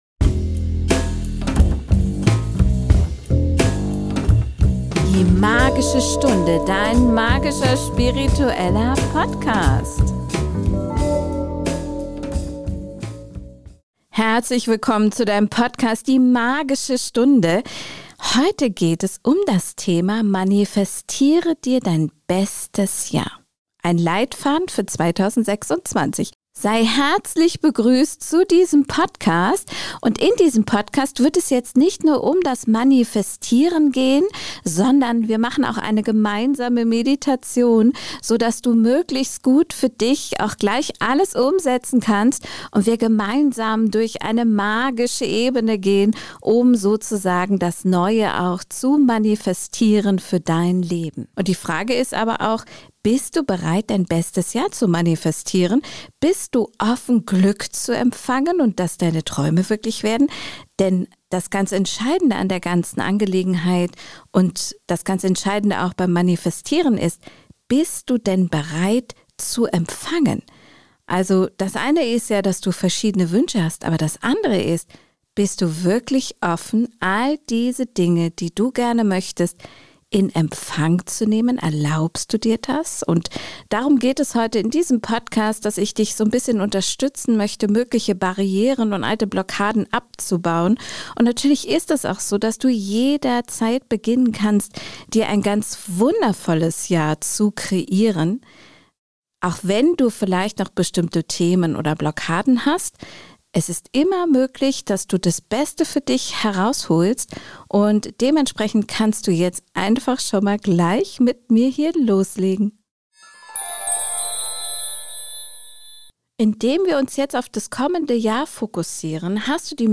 Mit einer geführten Meditation unterstütze ich dich, Ballast loszulassen und deine Herzenswünsche klar auszusenden.